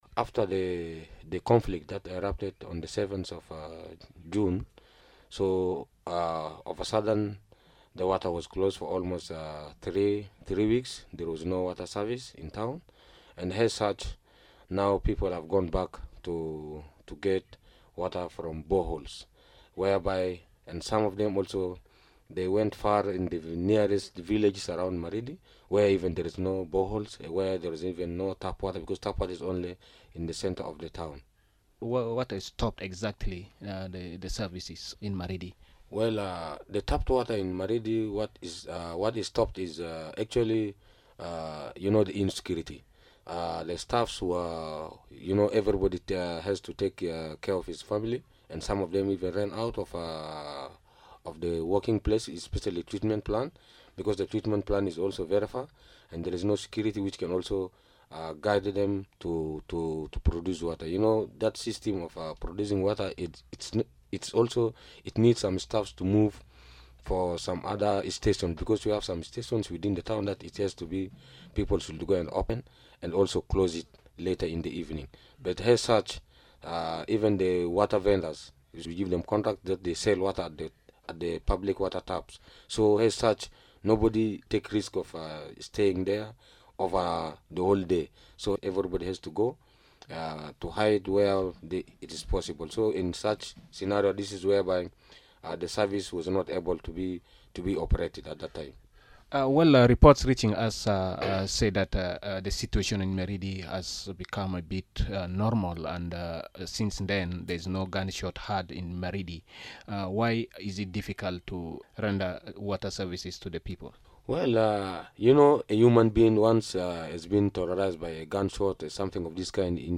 The Assistant Commissioner for Water in Maridi Daniel Wudada, says there is growing fear about an outbreak of water borne diseases.